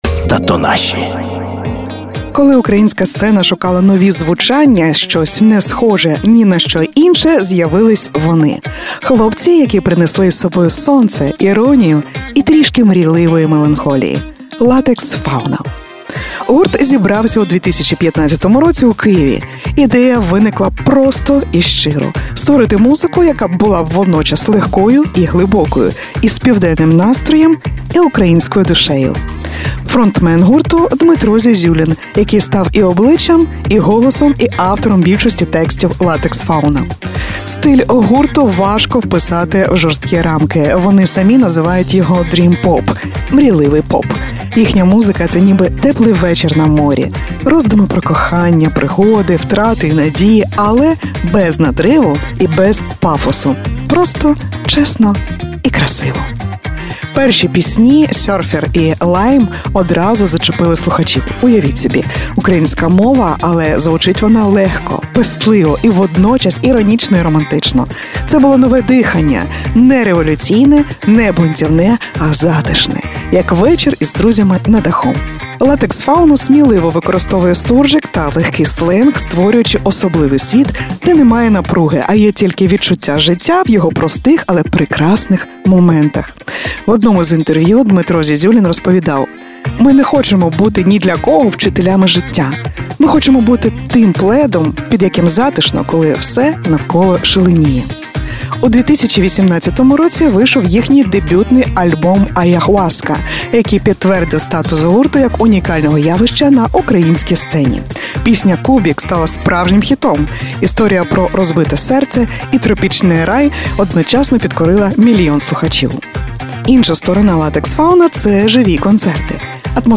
🌴 Ідея гурту виникла просто: створити музику з південним настроєм і українською душею.
🌀 Їхній стиль — dream pop.